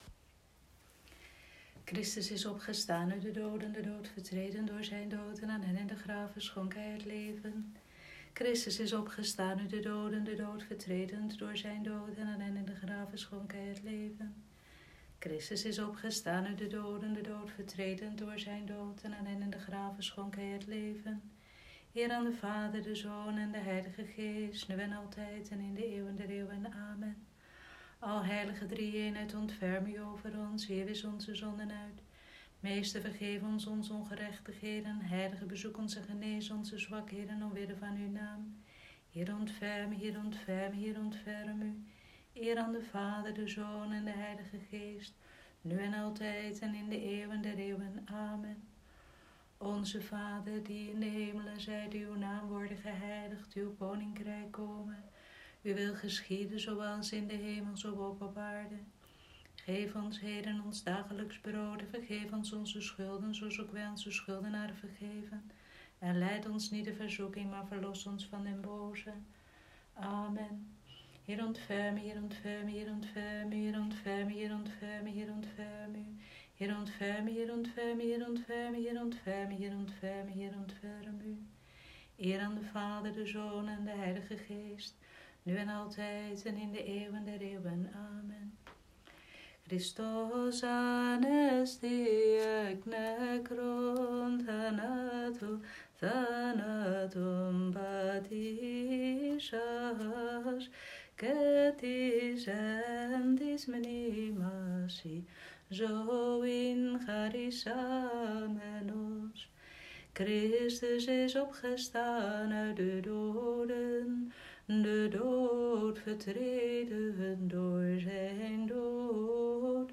Vespers vrijdagavond, 1 mei 2020